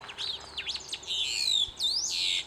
Scientific name: Leistes loyca catamarcanus
English Name: Long-tailed Meadowlark
Sex: Male
Life Stage: Adult
Province / Department: Catamarca
Condition: Wild
Certainty: Filmed, Recorded vocal